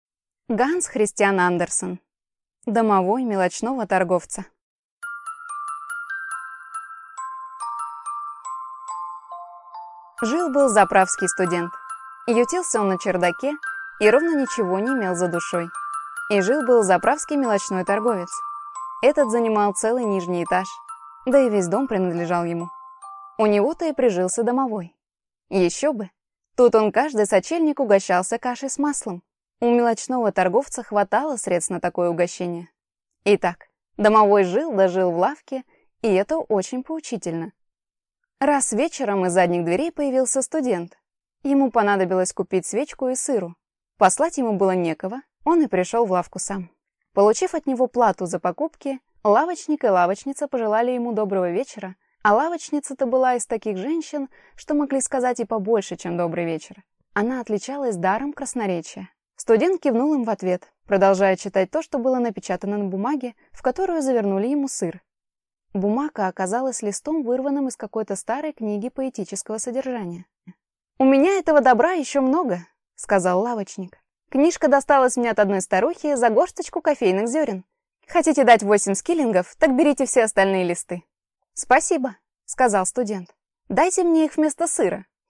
Аудиокнига Домовой мелочного торговца | Библиотека аудиокниг